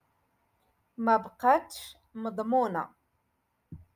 Moroccan Dialect- Rotation Six - Lesson Four